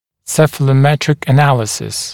[ˌsefələˈmetrɪk ə’næləsɪs][ˌсэфэлэˈмэтрик э’нэлэсис]цефалометрический анализ